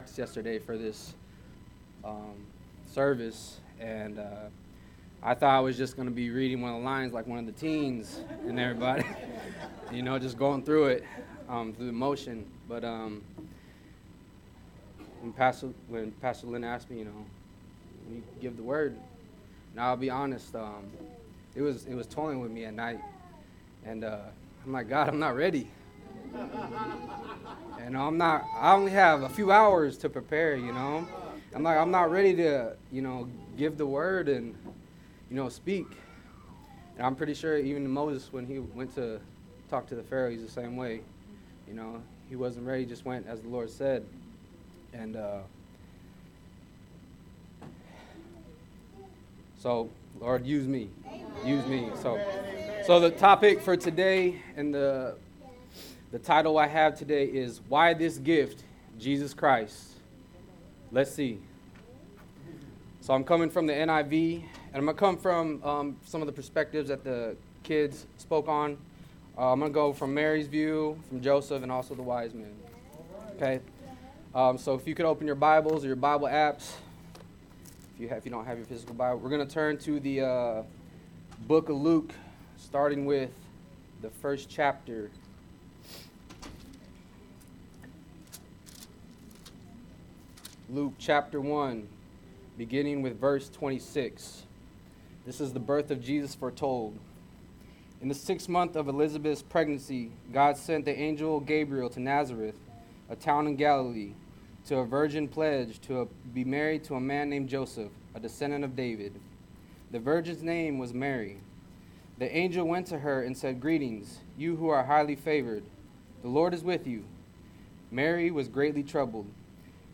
Sermons by Living Faith Christian Center OH